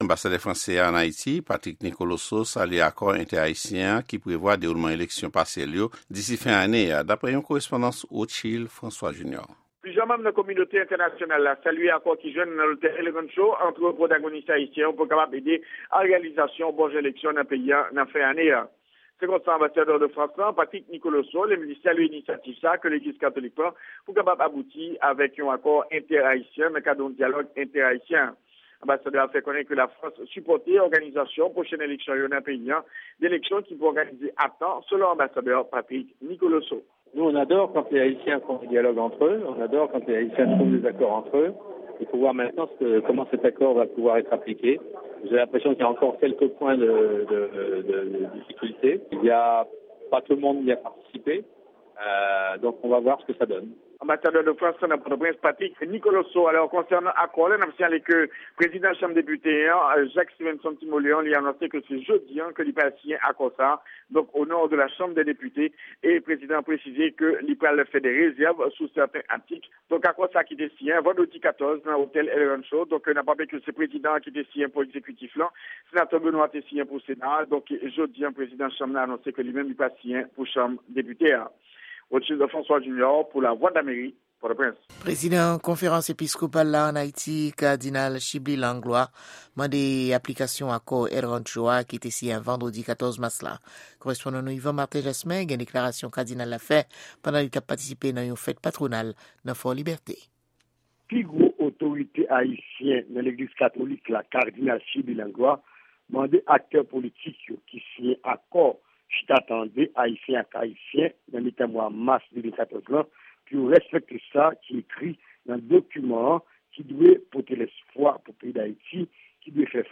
Repòtaj : Reyaksyon Lafrans, Inyon Ewòp ak PNUD sou Akò Entè-Ayisyen an